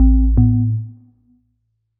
Melodic Power On 9.wav